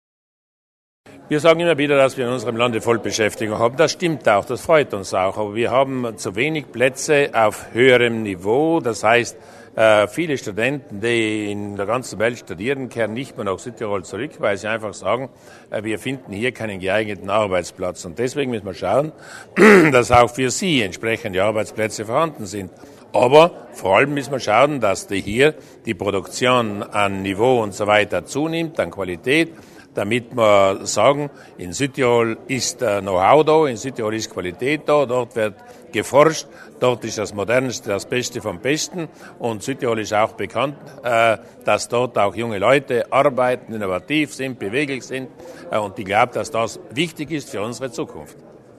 Landeshauptmann Durnwalder zur Bedeutung von Innovation